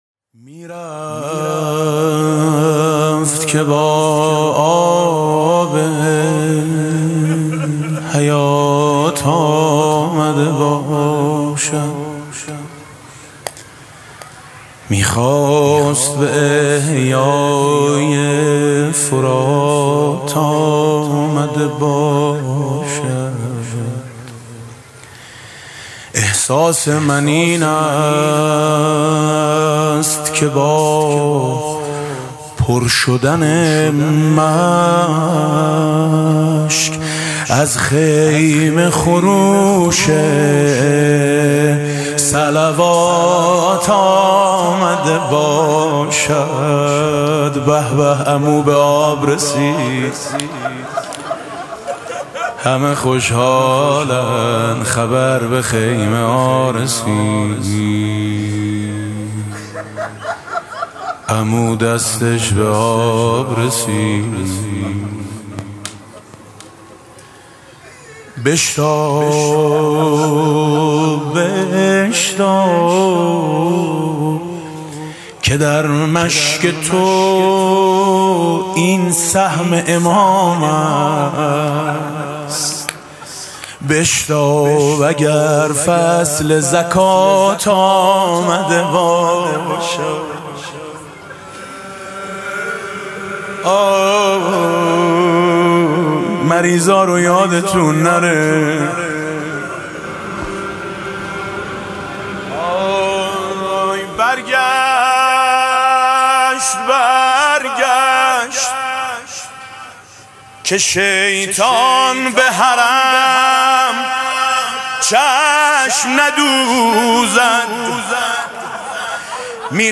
شب نهم محرم ۱۴۰۰
music-icon روضه: شاید عمو از راه فرات آمده باشد حاج میثم مطیعی